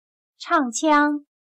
唱腔\chàngqiāng\Las melodías cantadas por actores de ópera y canto. Varía según el tipo de obra y las habilidades de canto de los actores.